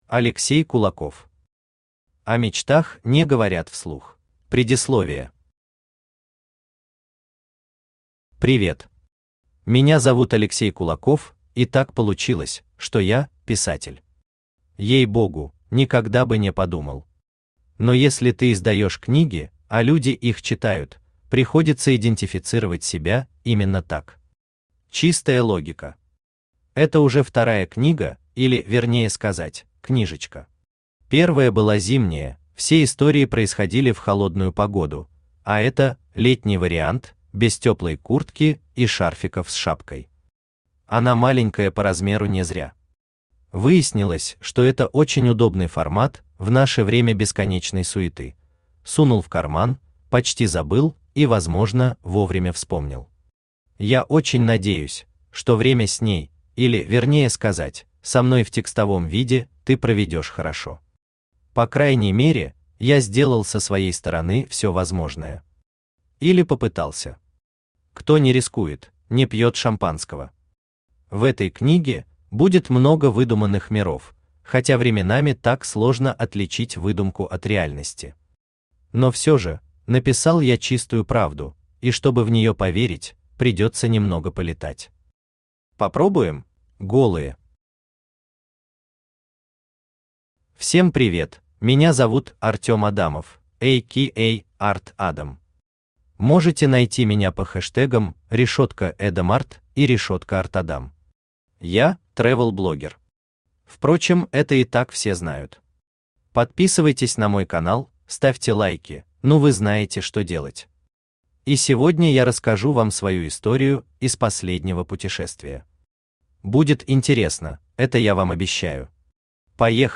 Аудиокнига О мечтах не говорят вслух | Библиотека аудиокниг
Aудиокнига О мечтах не говорят вслух Автор Алексей Олегович Кулаков Читает аудиокнигу Авточтец ЛитРес.